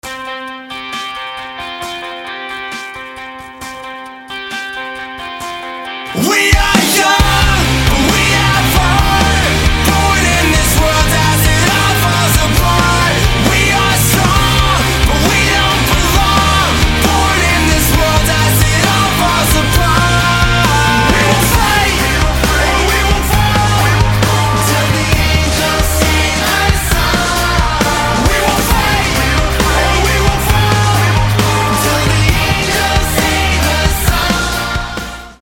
• Качество: 320, Stereo
громкие
Rap-rock
nu metal